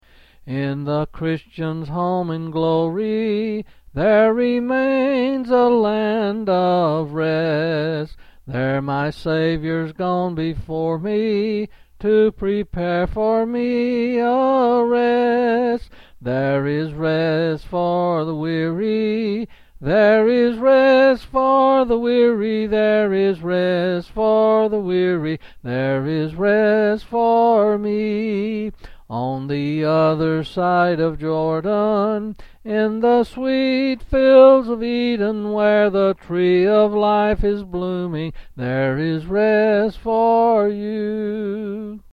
quill Selected Hymn
8s and 7s